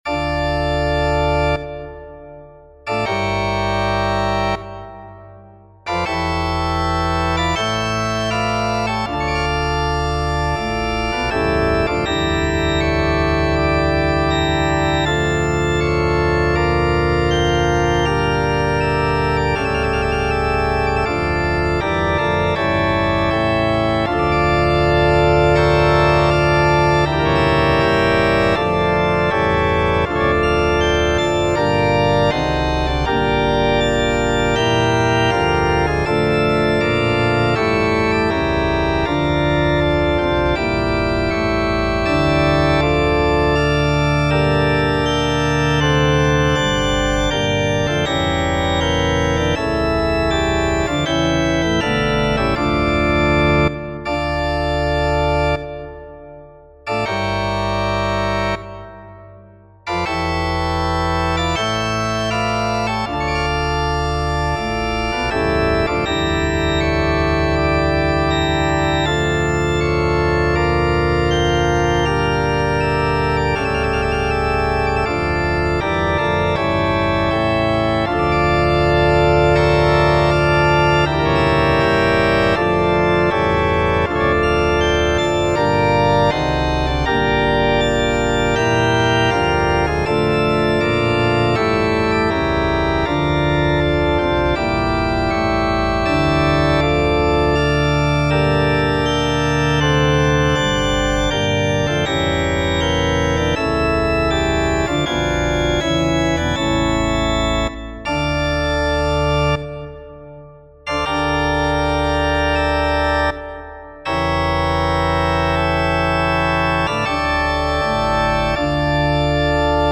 - Piano Music, Solo Keyboard
Prelude and Fugue in D minor.